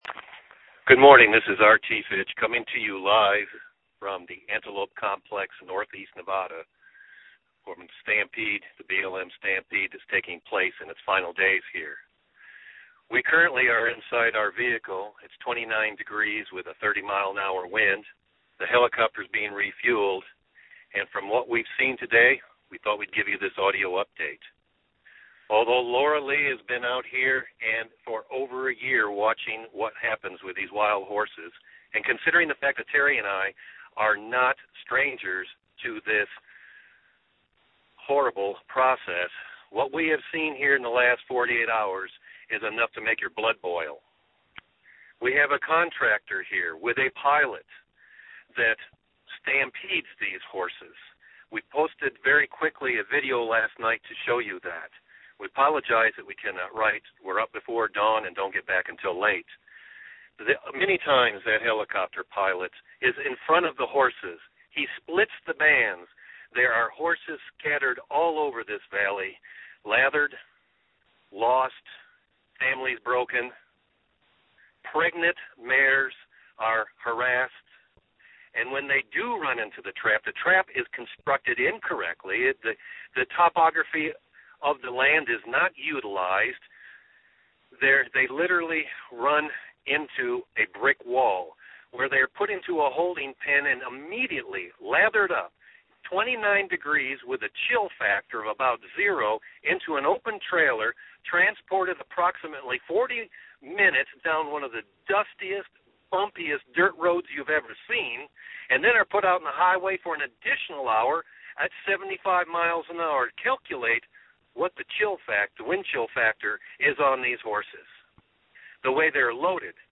I can hear and feel the pain and disgust in your voice.
I can hear the urgency in your voice, and I hear your message loud and clear.